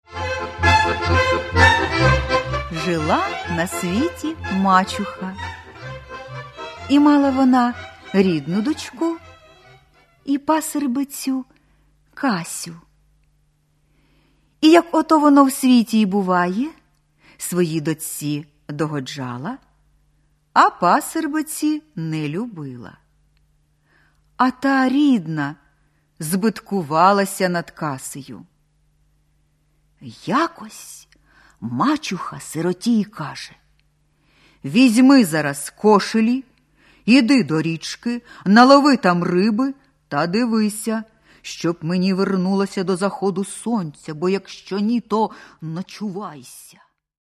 Каталог -> Аудіо книги -> Дитяча література